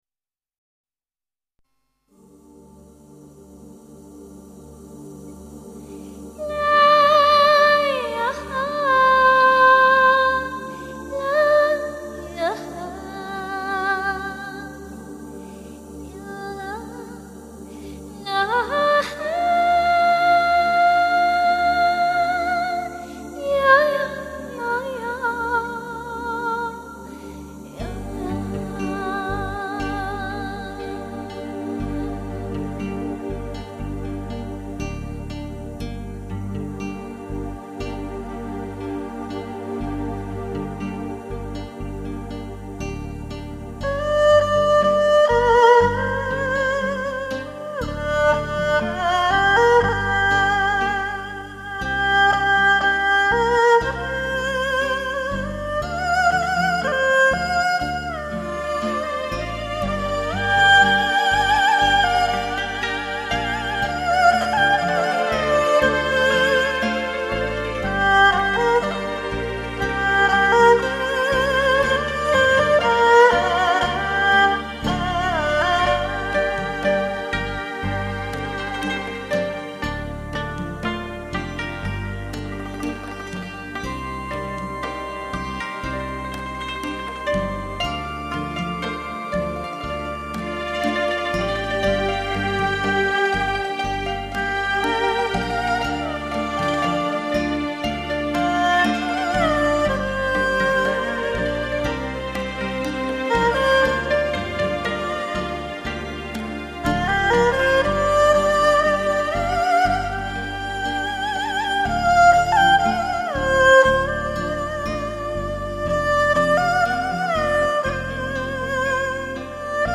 音质很棒。带着中国民族音乐浓郁风味的经典新世纪休闲音乐，绝对的超值珍藏品。
旋律清幽抒情